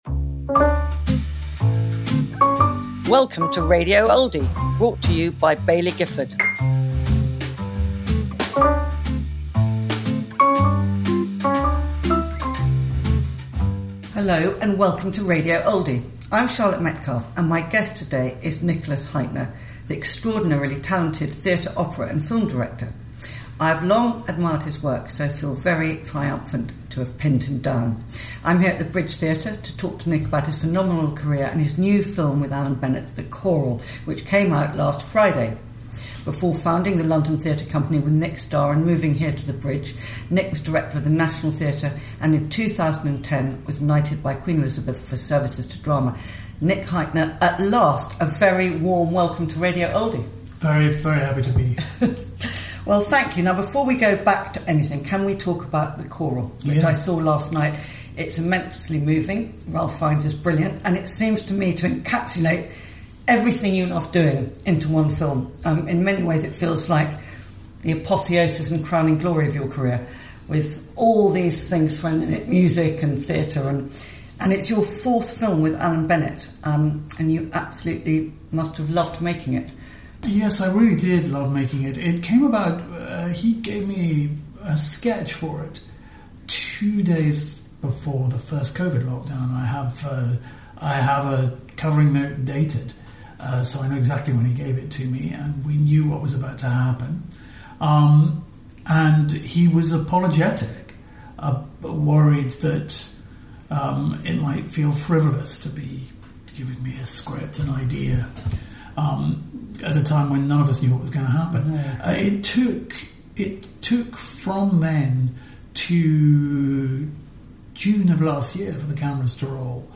Radio Oldie